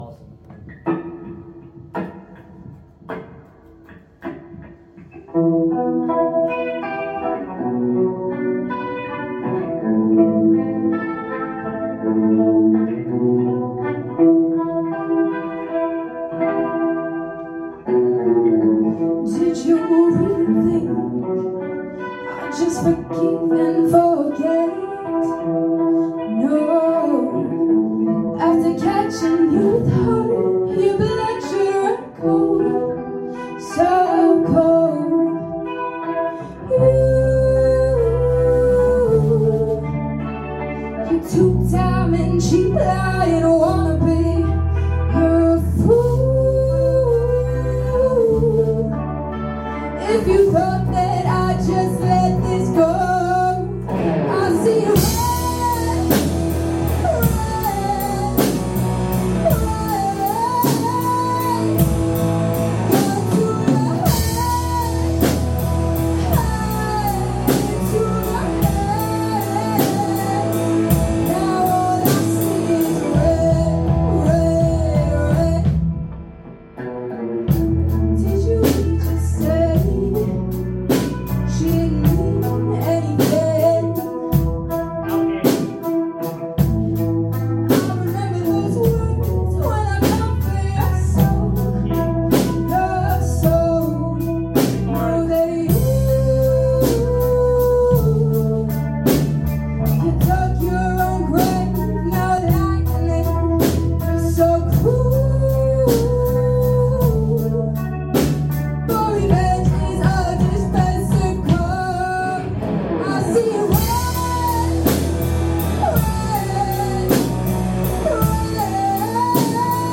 we are a 5 piece rock band
Cover